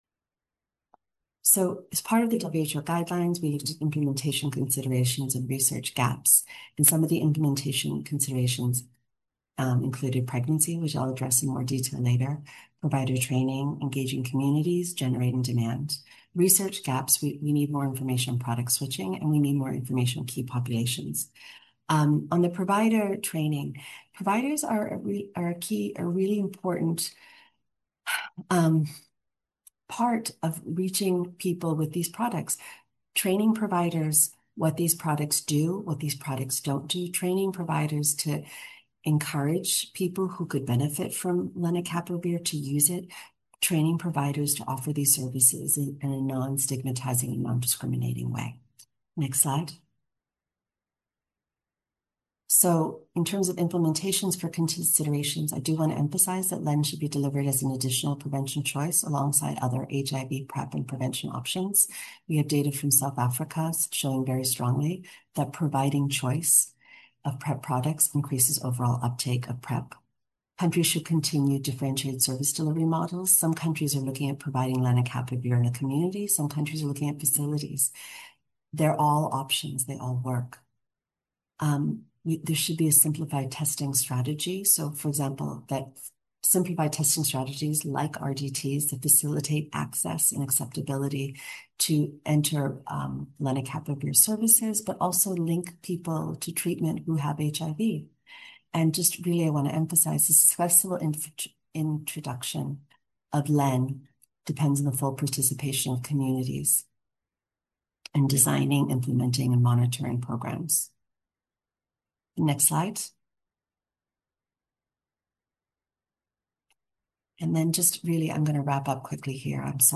HIV-Prevention-Webinar-Part-Two-.mp3